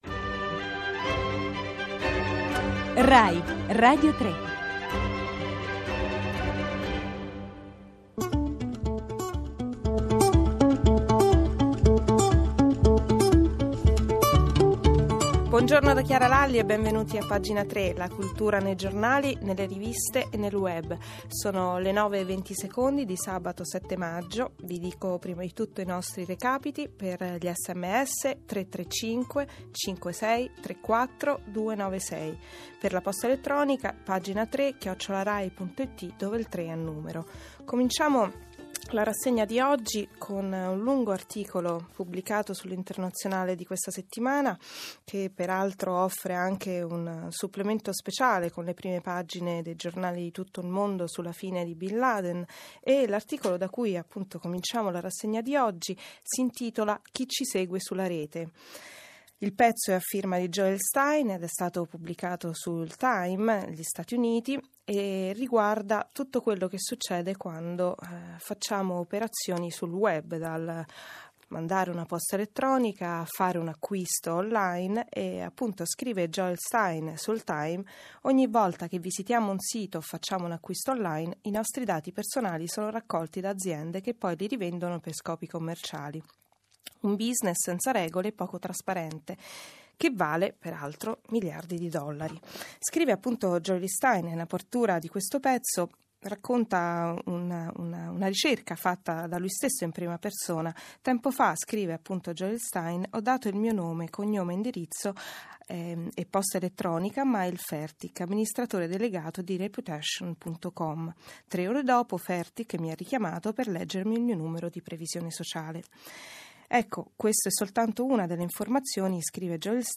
questo è il podcast originale del programma e qui di seguito l’intervista che ho estratto